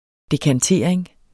Udtale [ dekanˈteɐ̯ˀeŋ ]